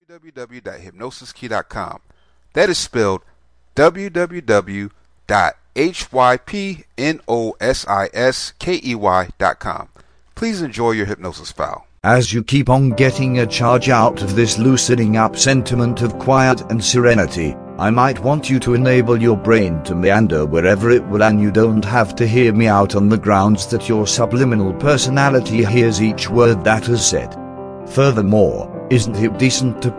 Stop Self Criticism Hypnosis Mp3
Welcome to Self Criticism Self Hypnosis Mp3, this is a powerful hypnosis script that helps you stop self criticism.